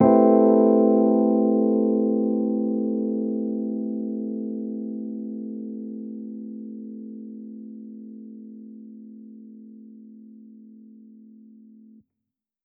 Index of /musicradar/jazz-keys-samples/Chord Hits/Electric Piano 2
JK_ElPiano2_Chord-Am9.wav